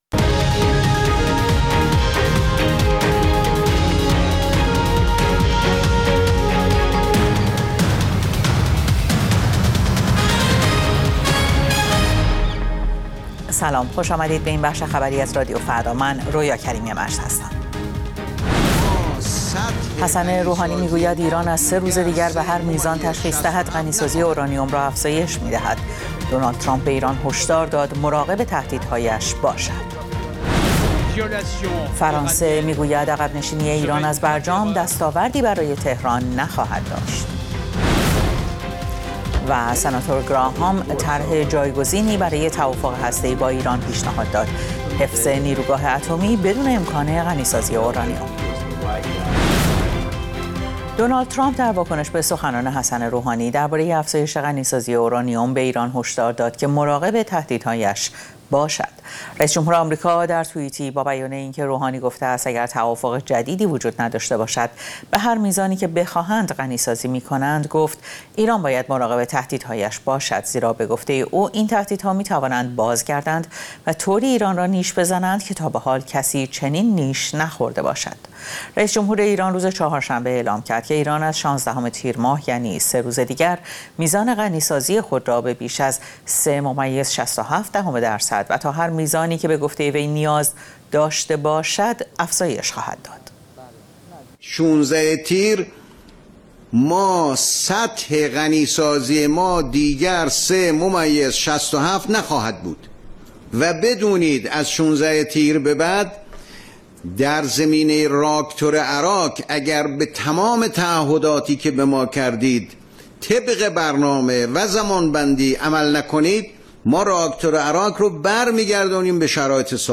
اخبار رادیو فردا، ساعت ۱۰:۰۰